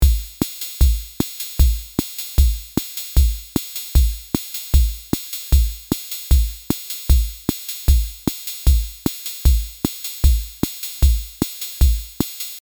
The library contains 16 drum loops from the built-in drum machine of the rare USSR electro-bayan «Orion» in collector’s quality 96 kHz, 32 bit float, with a total volume of 83 MB.